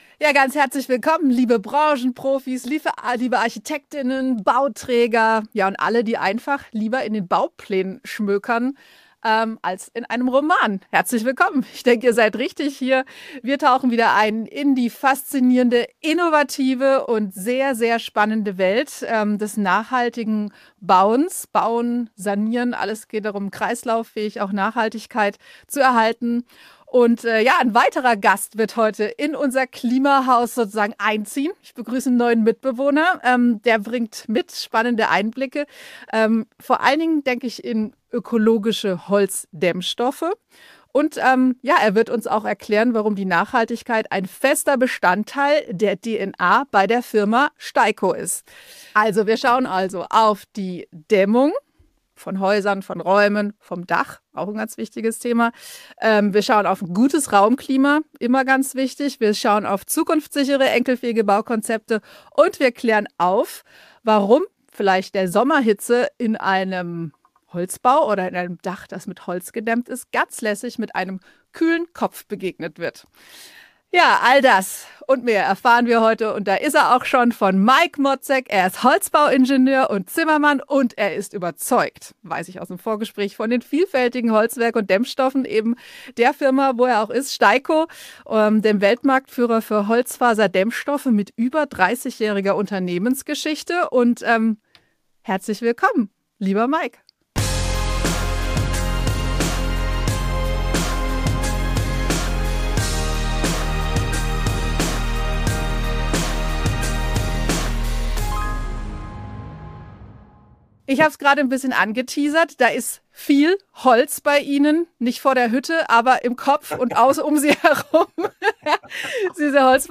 Ein Gespräch über Praxis, Planung und das gute Gefühl, wenn Nachhaltigkeit nicht nur messbar, sondern spürbar wird.